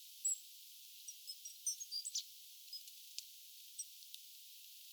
hömötiaislintu, 1
homotiaislintu.mp3